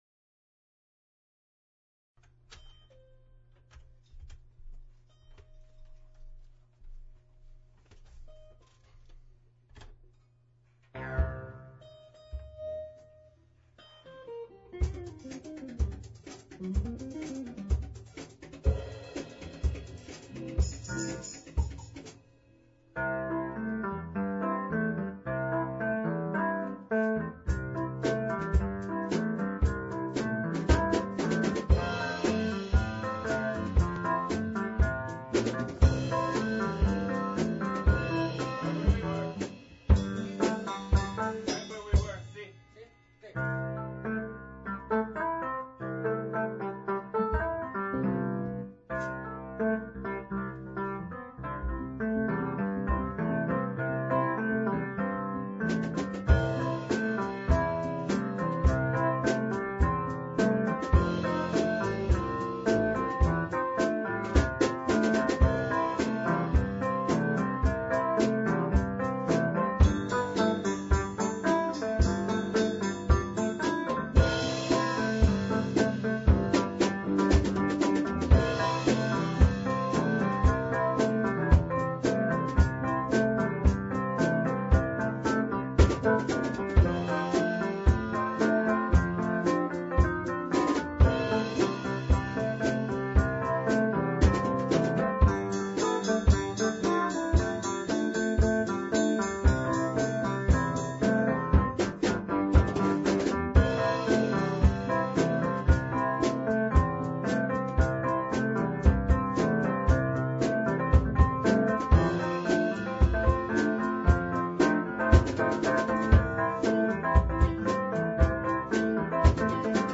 Rehearsal